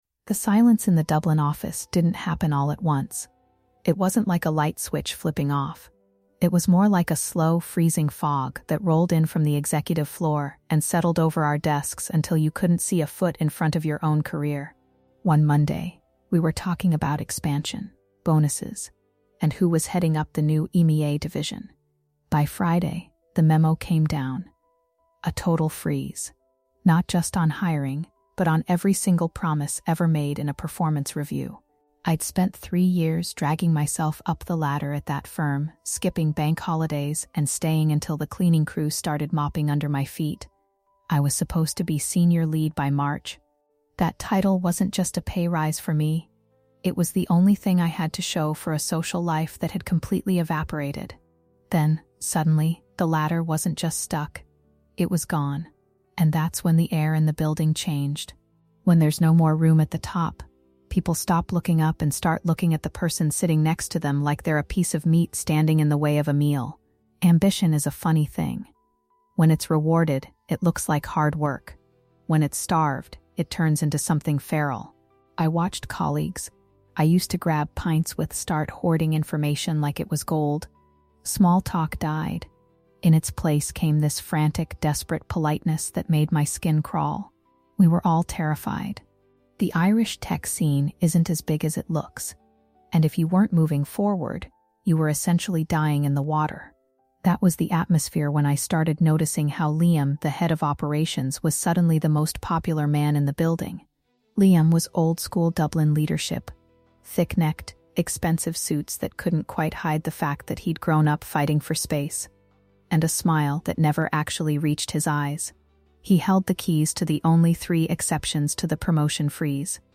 In this episode of Workplace Entanglement, we step inside a high-stakes Dublin office where a sudden promotion freeze turns professional ambition into a desperate fight for survival. Narrated by a woman who spent three years sacrificing her personal life for a title that vanished overnight, this story explores the dark shift that happens when hard work is no longer the currency of success.